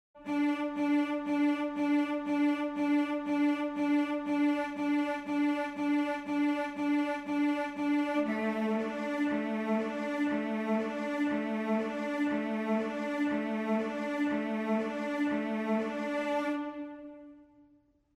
Сольная скрипка A с нажатой педалью имеет 3 RR, но на настоящую смену смычка не похоже.. в примере Con Moto виолончели: 8 одинаковых нот без педали, потом с педалью, затем на двух разных нотах без педали/с педалью.